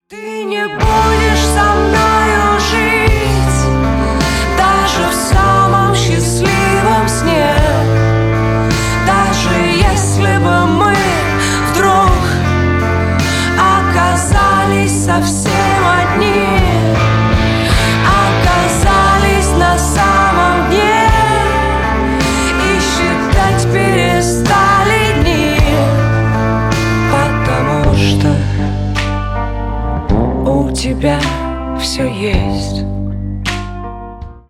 грустные
рок